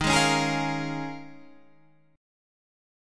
D#9sus4 chord